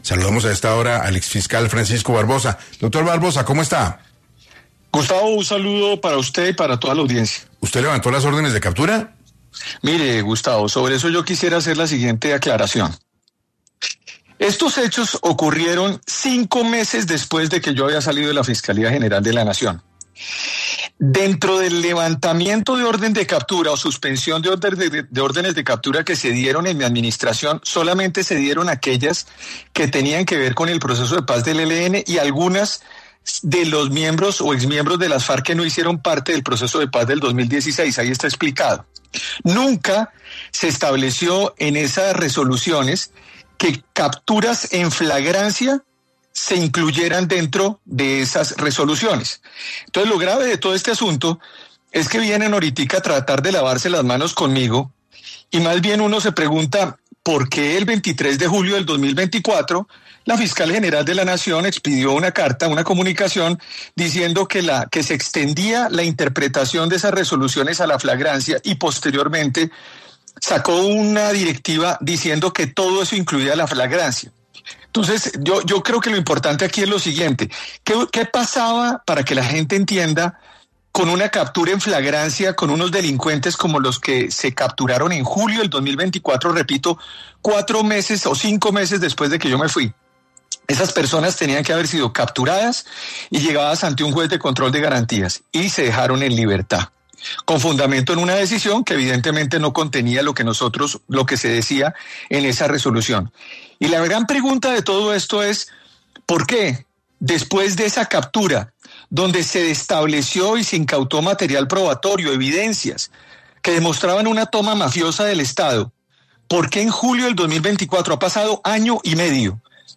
Entre las preguntas que se están haciendo en el momento es ¿por qué a miembros de las disidencias de Alias Calarcá se les había levantado las ordenes de captura?, en ese sentido, el exfiscal General de la Nación, Francisco Barbosa habló en 6AM.